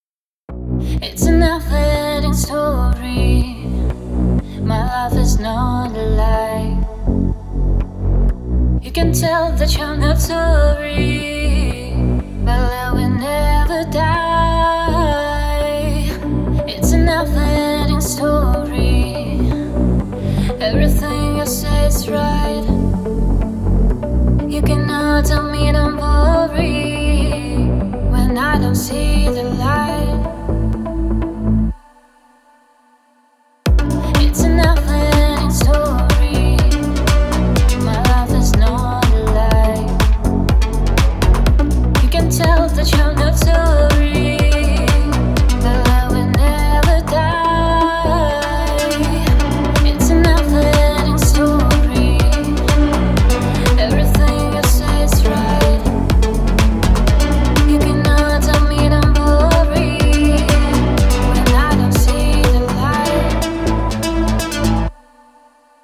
Где бас гитара Пересмотрел подход к басу в первом примере. Звуки лучше не подбирал, просто по другому Kick и Bass сложил.